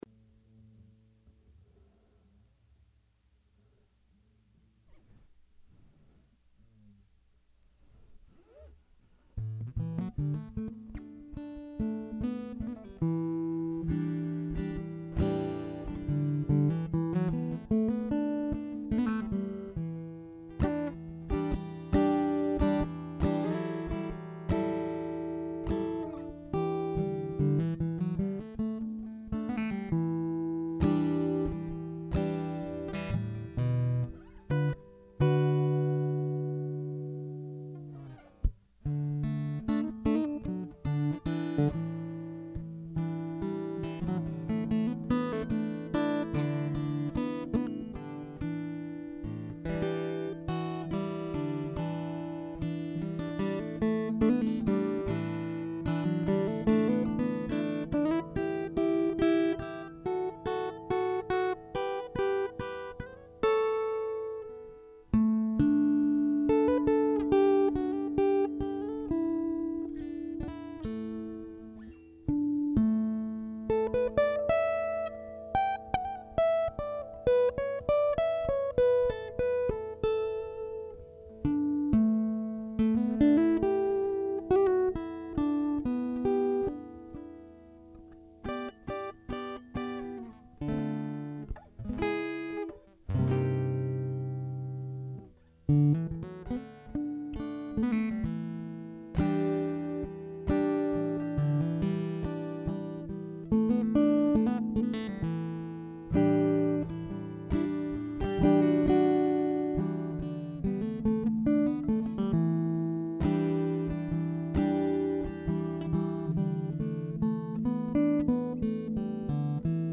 "Girl in the Moon" Soft acoustic guitar solo
The others were recorded at Purple Productions, using only a large diaphragm vocal mic about four feet away from where I sat with a guitar.
"Girl in the Moon" features the Ovation.